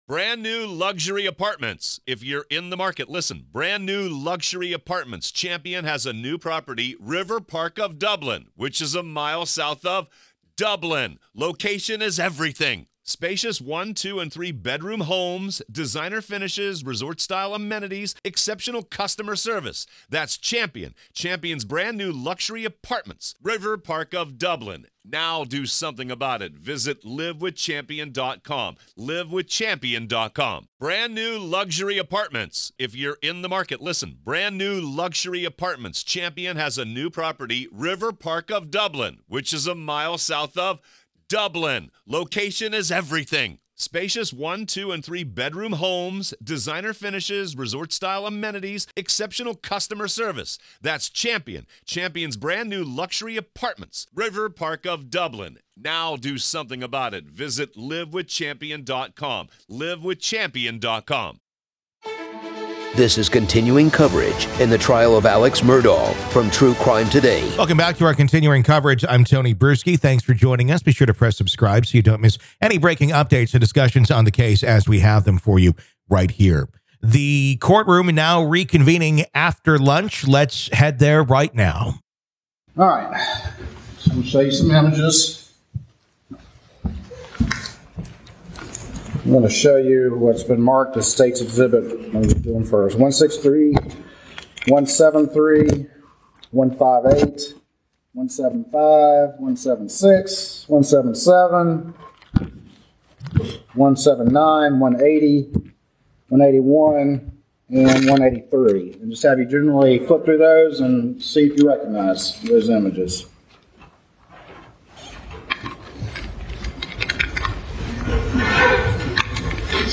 Facebook Twitter Headliner Embed Embed Code See more options This is our continuing coverage of the Alex Murdaugh murder trial.